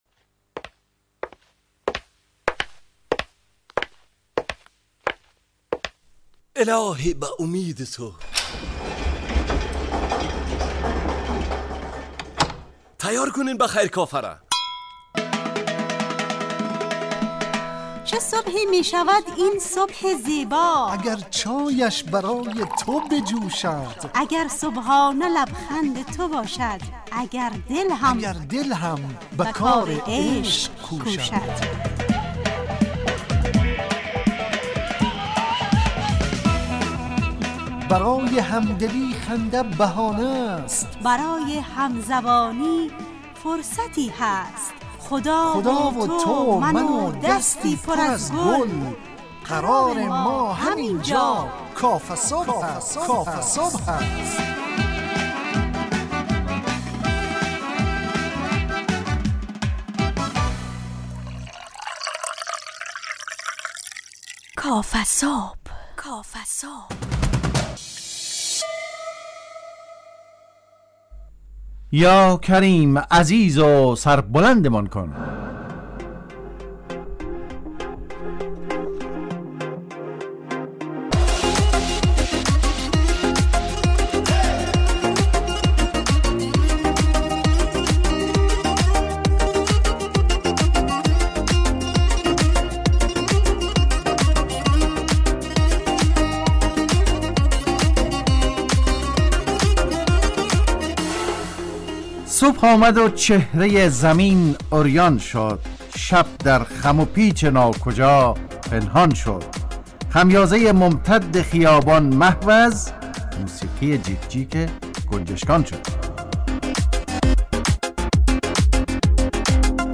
کافه‌صبح – مجله‌ی صبحگاهی رادیو دری با هدف ایجاد فضای شاد و پرنشاط صبحگاهی همراه با طرح موضوعات اجتماعی، فرهنگی و اقتصادی جامعه افغانستان با بخش‌های کارشناسی، نگاهی به سایت‌ها، گزارش، هواشناسی٬ صبح جامعه، گپ صبح و صداها و پیام‌ها شنونده‌های عزیز